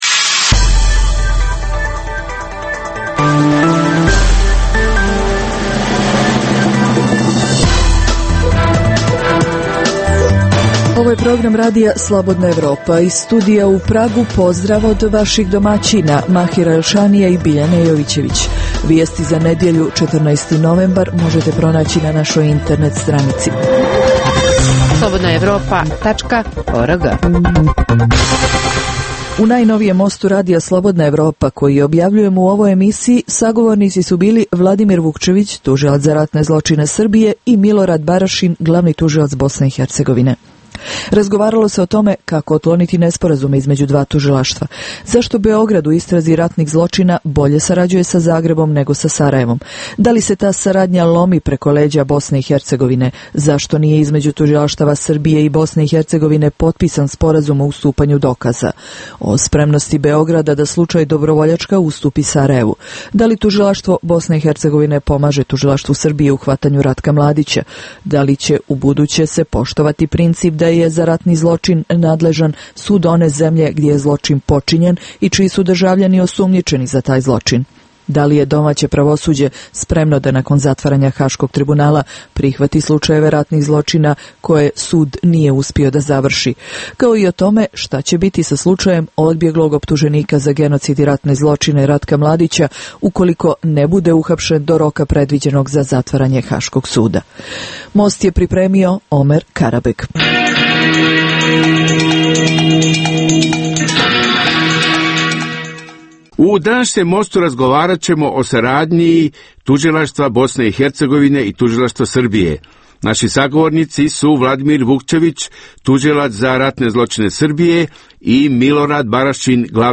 u kojem ugledni sagovornici iz regiona diskutuju o aktuelnim temama. Drugi dio emisije čini program "Pred licem pravde" o suđenjima za ratne zločine na prostoru bivše Jugoslavije, koji priređujemo u saradnji sa Institutom za ratno i mirnodopsko izvještavanje iz Londona.